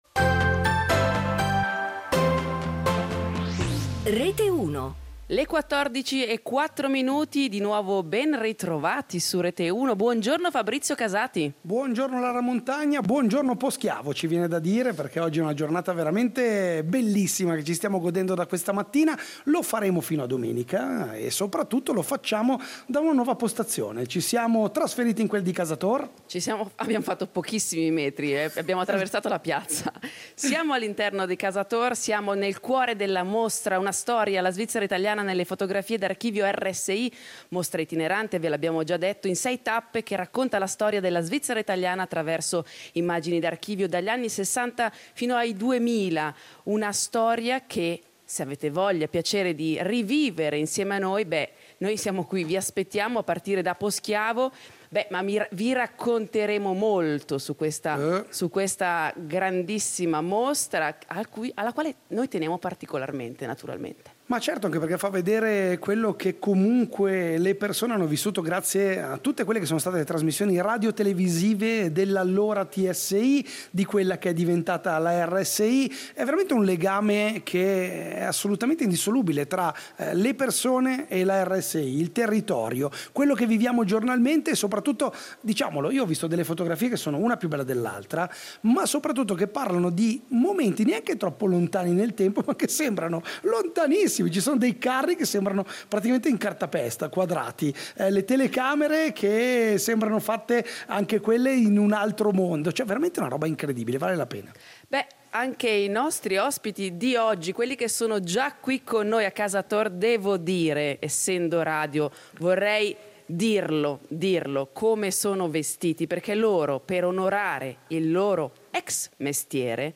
Tra dogane e contrabbando, in collegamento dai due villaggi dirimpettai di Cavaione e Viano.